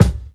KICK_KEEP_BELIEVING.wav